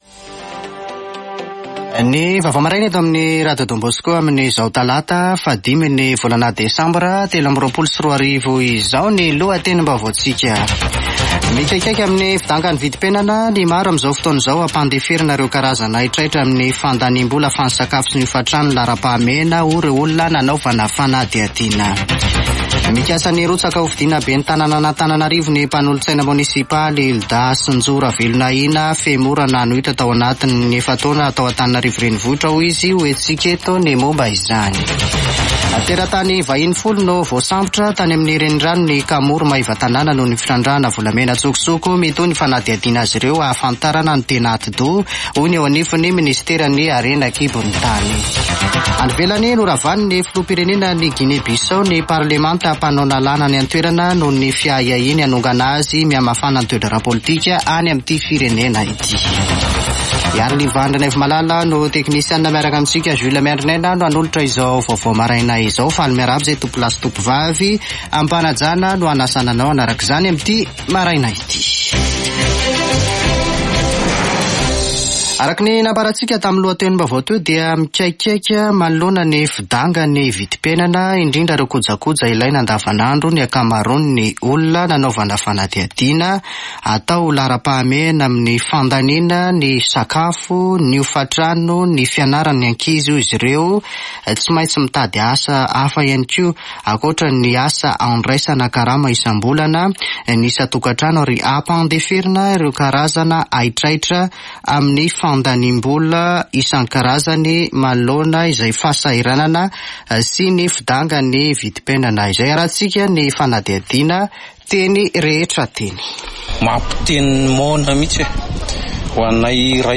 [Vaovao maraina] Talata 5 desambra 2023